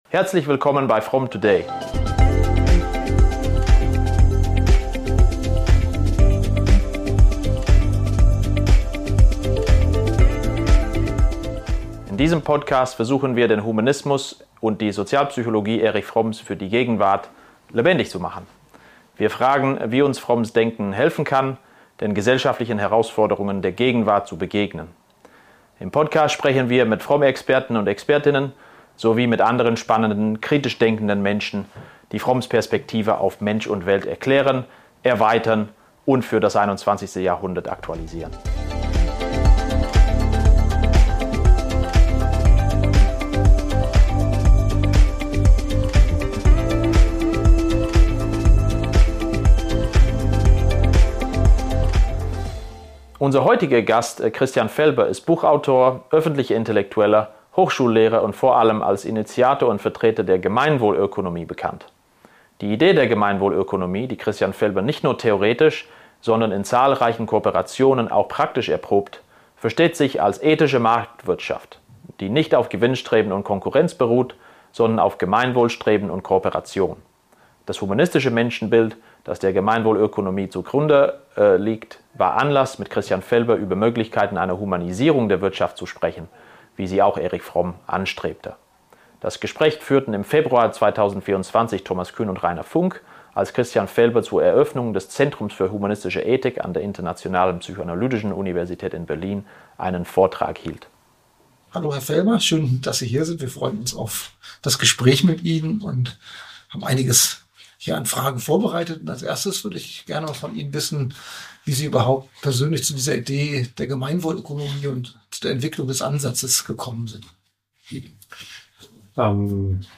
How can Fromm's thinking help us to face the social challenges of our time? In the podcast, we talk to Fromm experts and other interesting people who explain and expand Fromm's perspective and apply it to the 21st century.